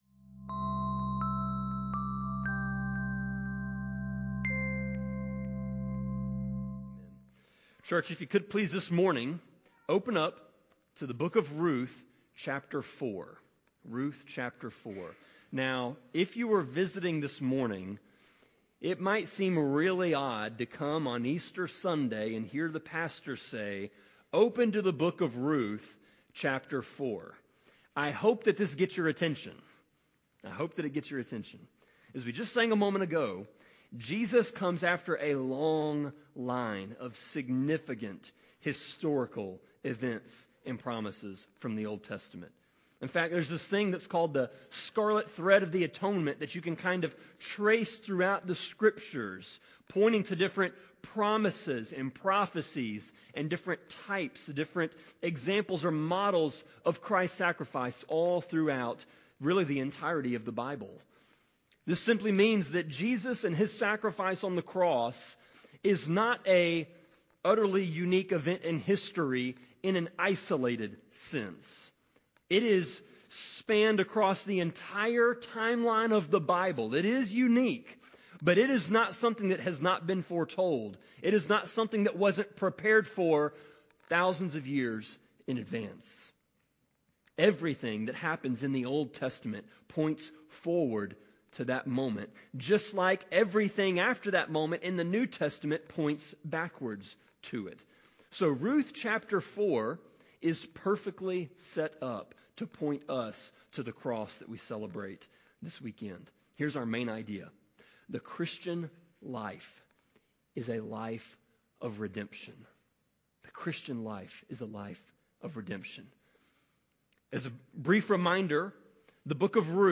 Sermon-26.4.5-CD.mp3